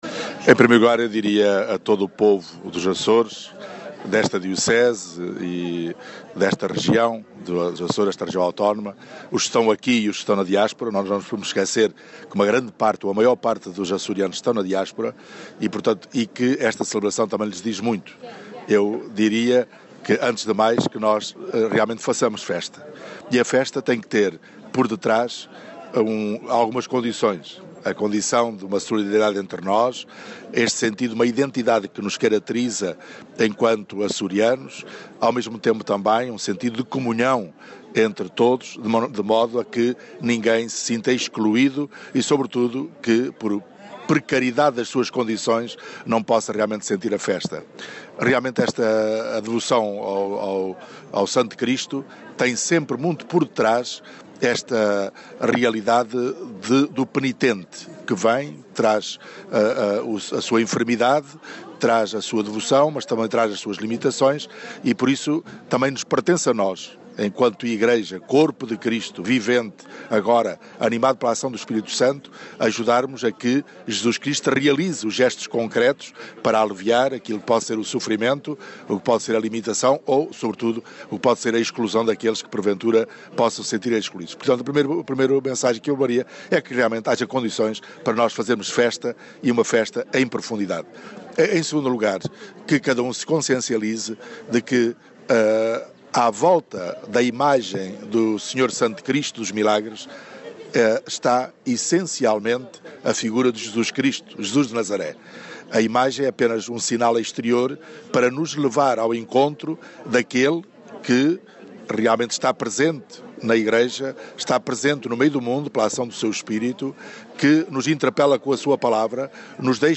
ZOOM0052_mensagem_sr_bispo_festa_sto_cristo_sm_2018